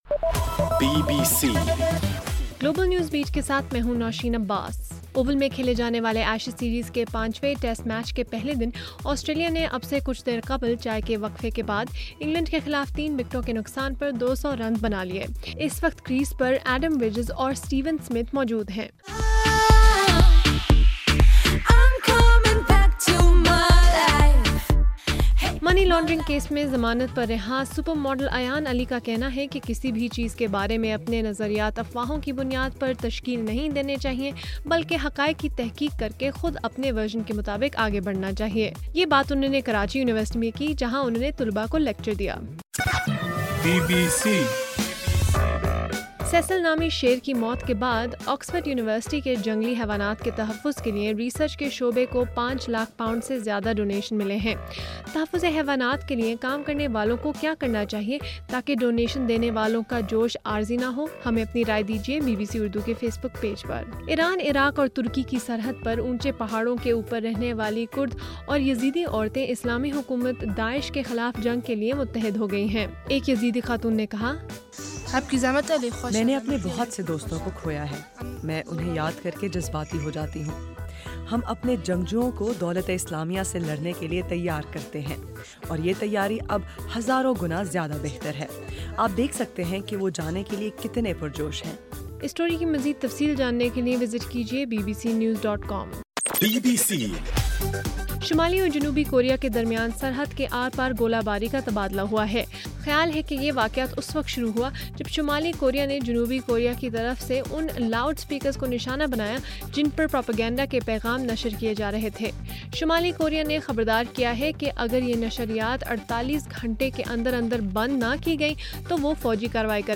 اگست 20: رات 10 بجے کا گلوبل نیوز بیٹ بُلیٹن